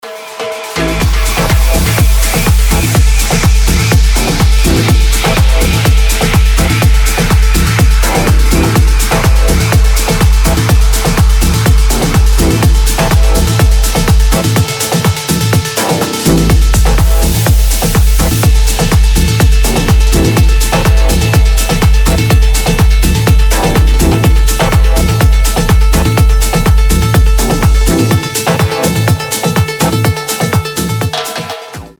deep house
Electronic
EDM
без слов
Tech House
techno
Стиль: tech house